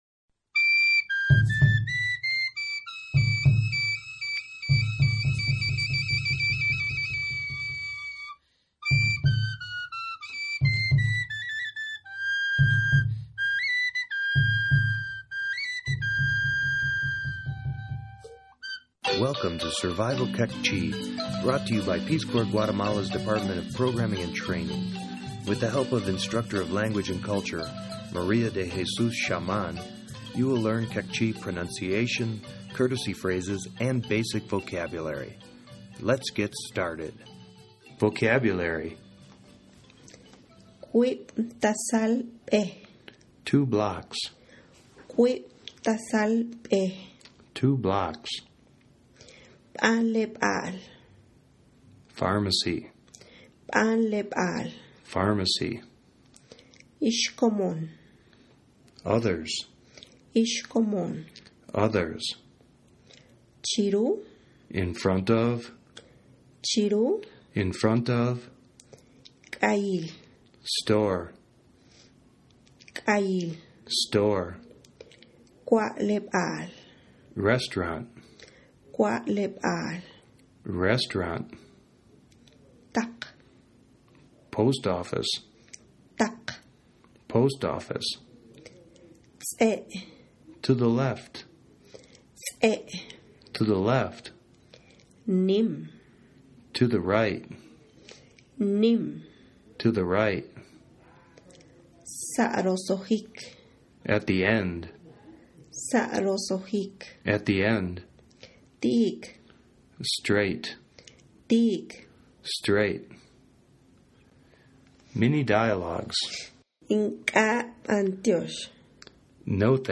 Survival Queqchi - Lesson 03 - Vocabulary, Dialogues, Meals_.mp3